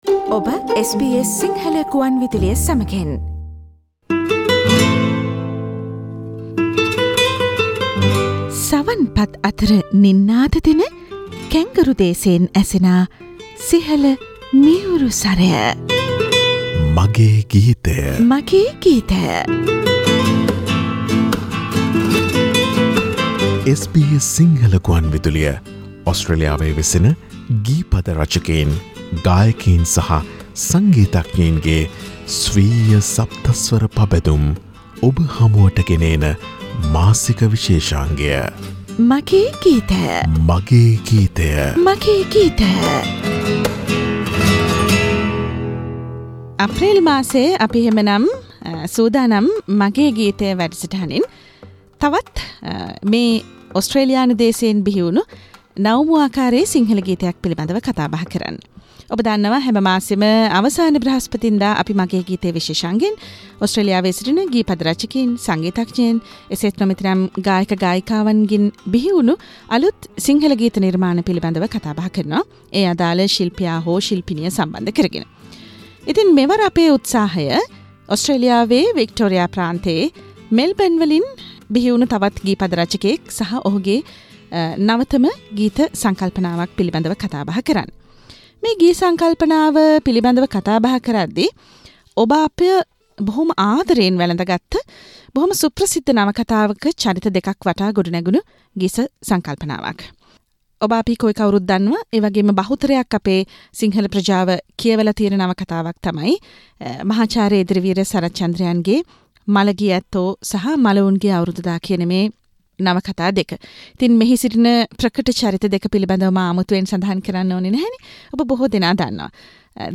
A chat with a lyrist